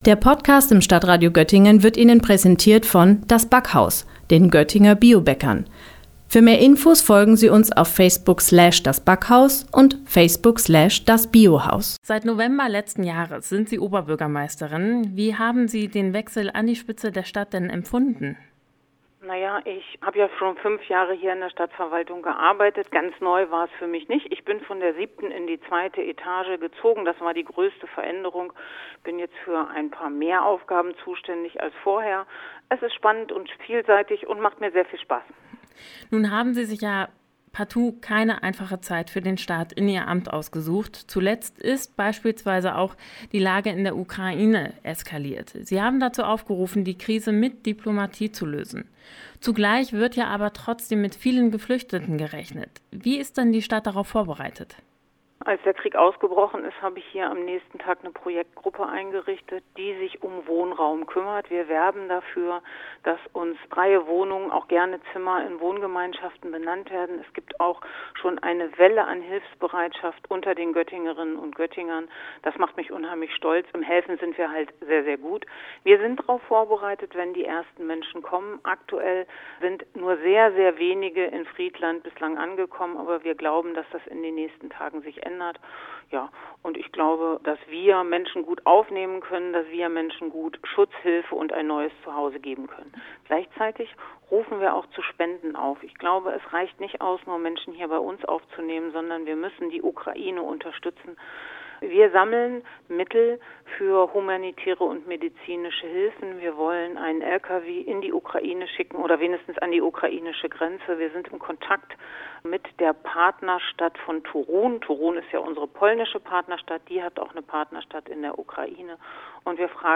Bezahlbarer Wohnraum und Neubauplanungen am Weender Tor – Göttingens Oberbürgermeisterin Petra Broistedt im Interview